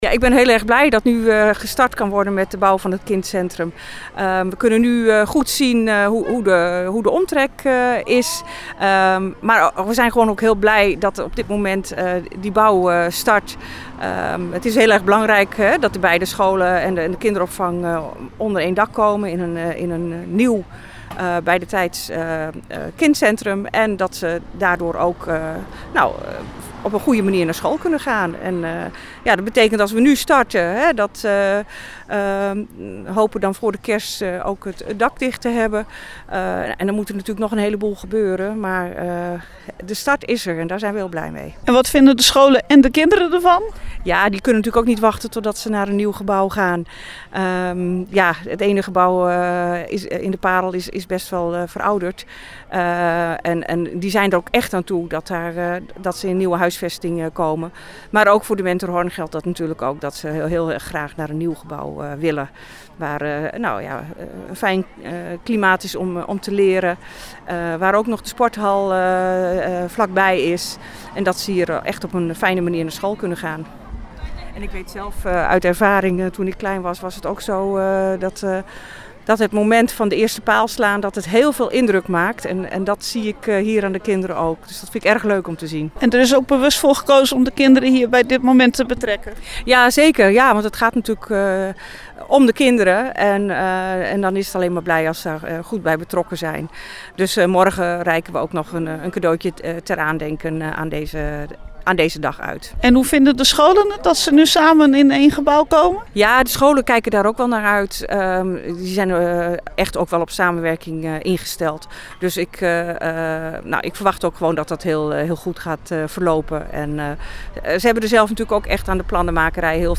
Luister hier het interview met wethouder Erianne van der Burg
Start-bouw-kindcentrum-Muntendam-wethouder-van-der-Burg-MG.mp3